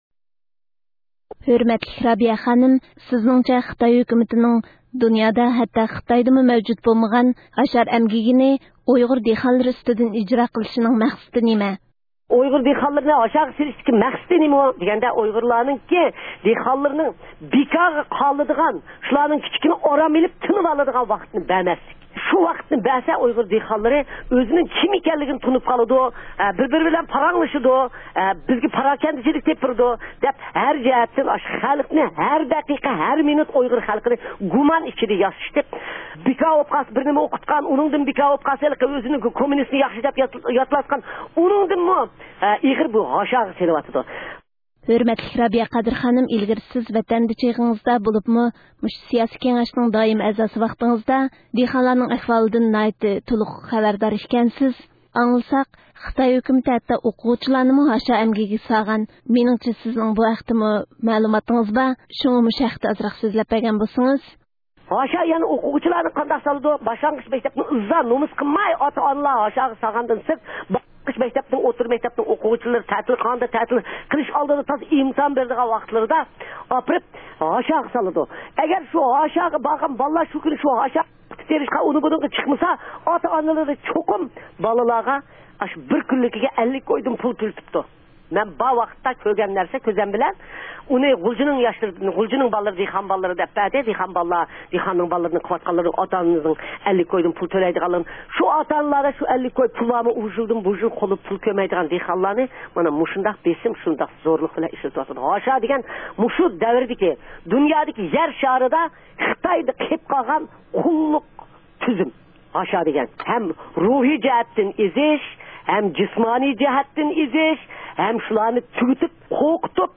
رابىيە قادىر خانىم رادىئومىز مۇخبىرى بىلەن ھاشار ھەققىدە سۆھبەت ئۆتكۈزدى